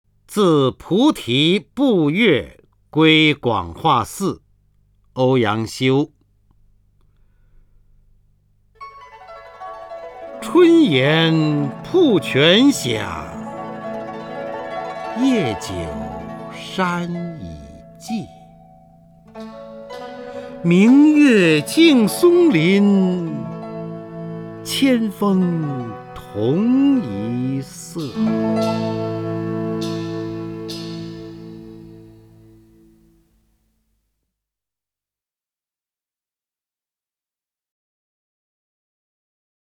首页 视听 名家朗诵欣赏 方明
方明朗诵：《自菩提步月归广化寺》(（北宋）欧阳修)